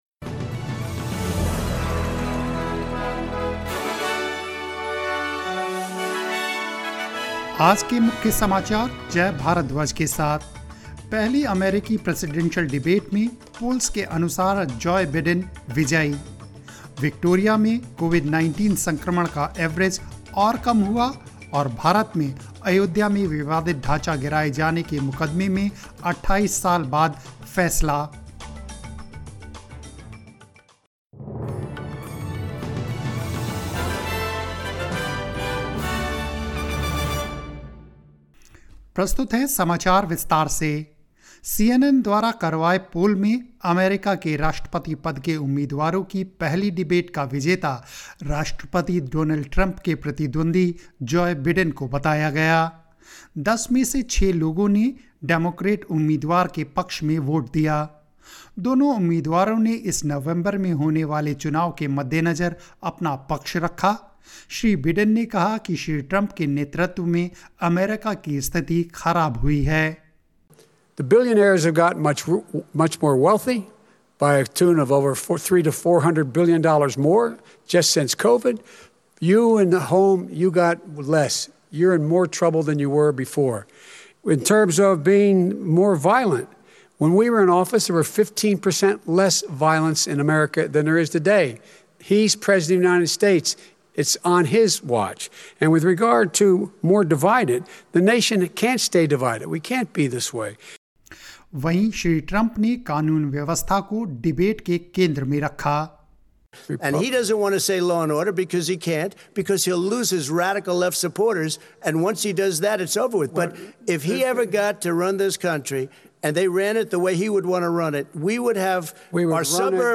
News in Hindi 30 September 2020